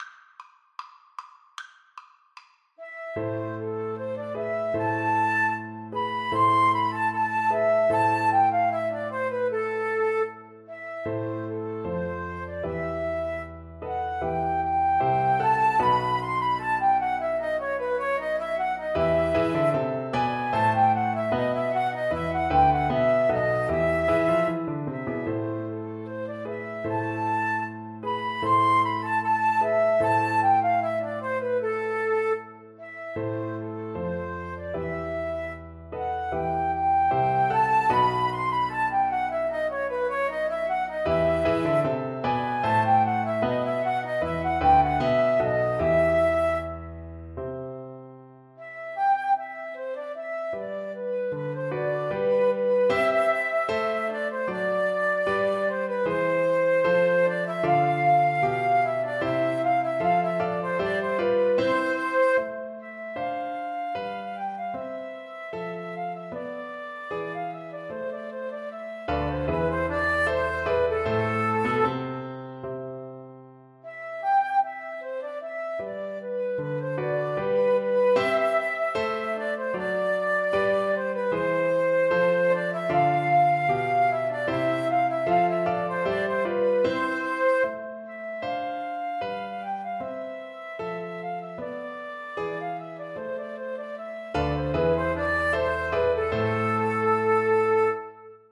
Allegro non troppo = c.76 (View more music marked Allegro)
4/4 (View more 4/4 Music)
Classical (View more Classical Flute Duet Music)